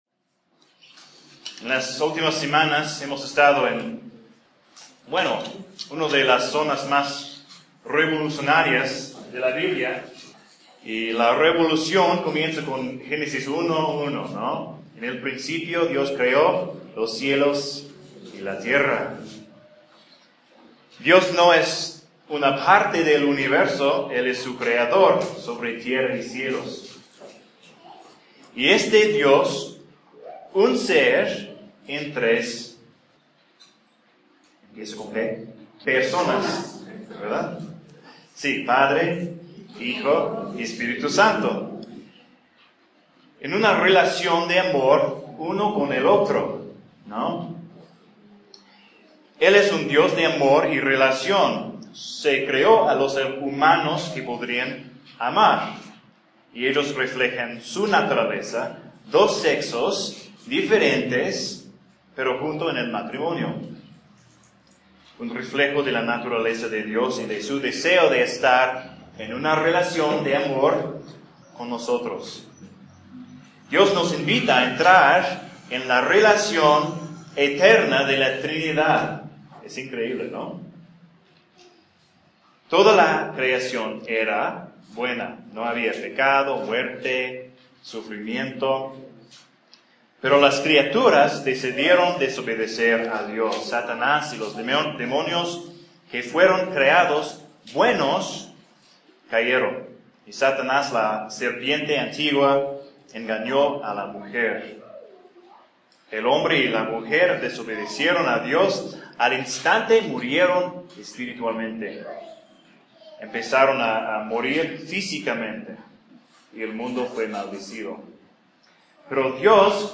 Un sermón de Génesis 4 – Dos Caminos.